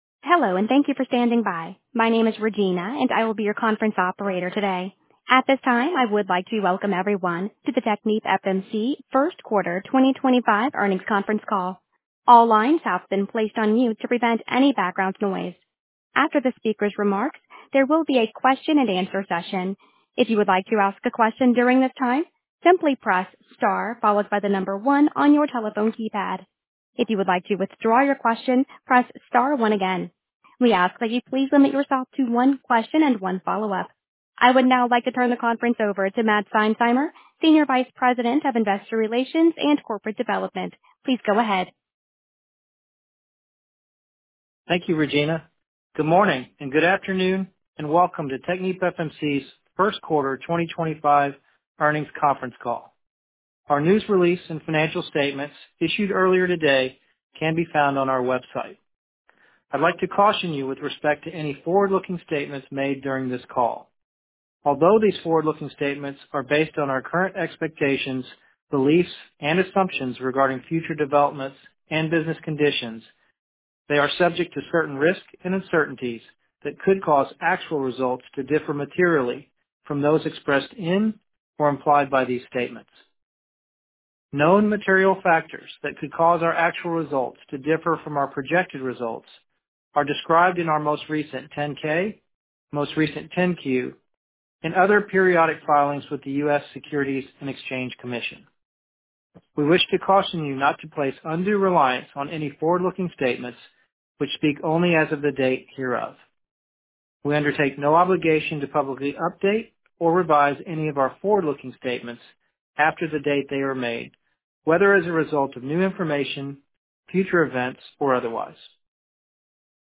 Technipfmc First Quarter 2025 Earnings Conference Call
technipfmc-first-quarter-2025-earnings-conference-call.mp3